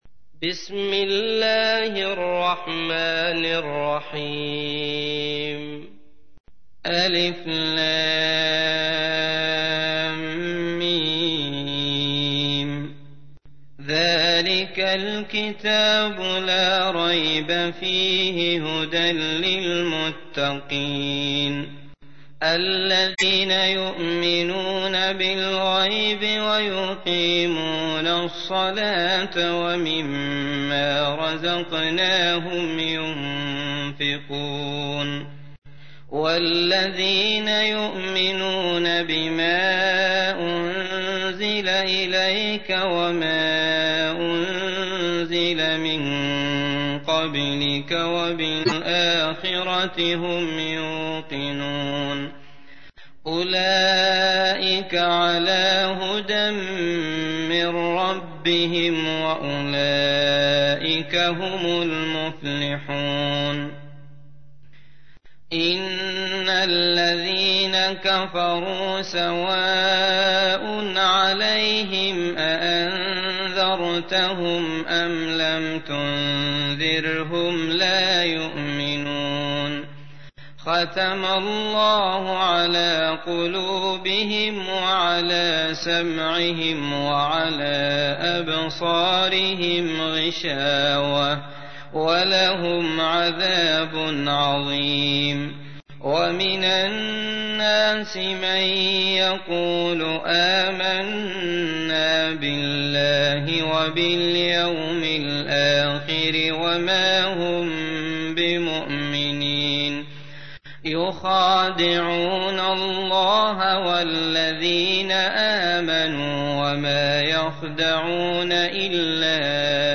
تحميل : 2. سورة البقرة / القارئ عبد الله المطرود / القرآن الكريم / موقع يا حسين